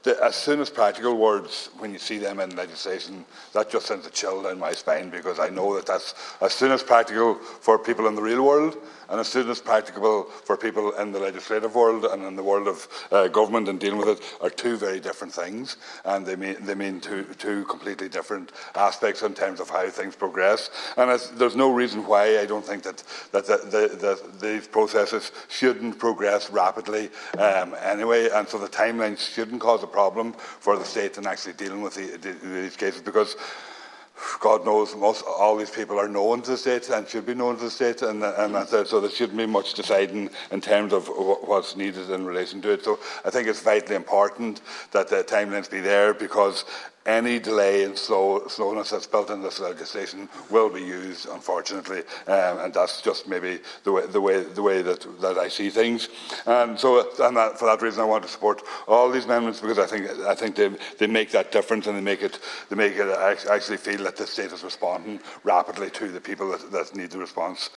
Deputy Pringle says time cannot be wasted in putting the adequate legislation in place: